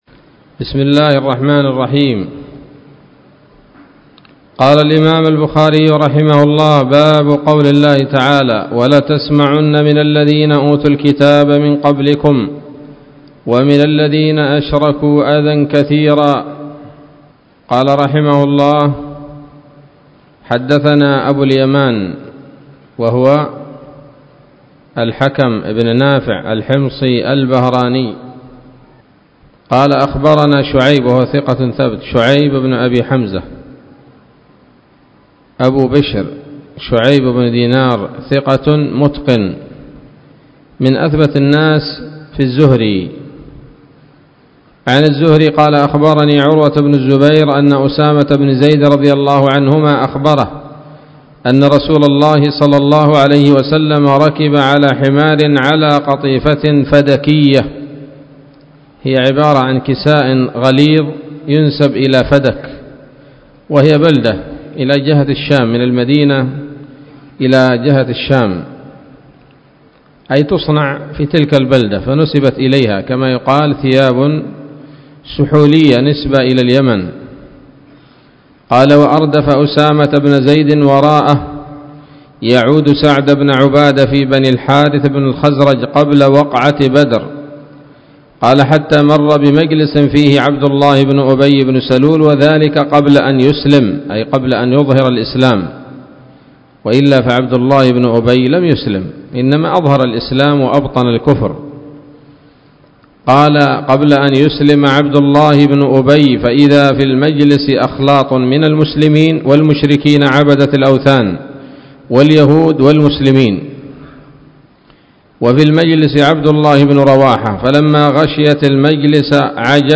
الدرس الثامن والخمسون من كتاب التفسير من صحيح الإمام البخاري